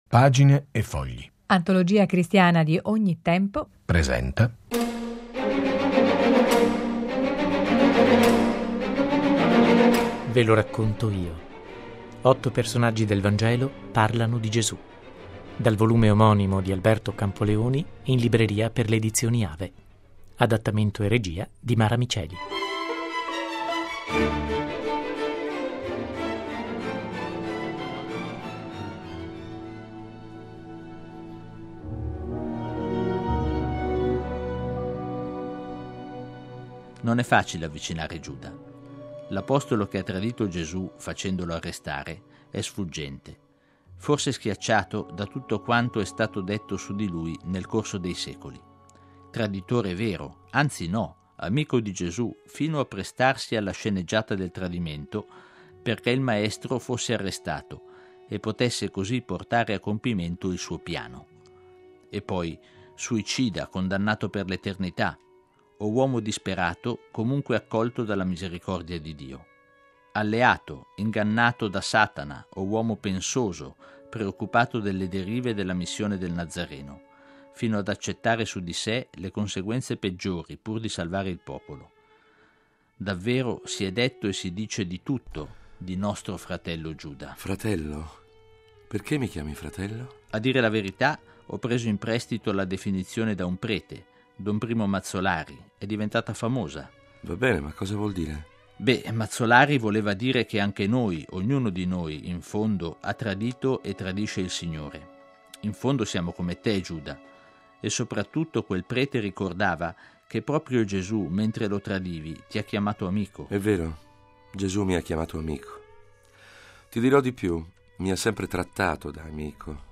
Radiodramma in due puntate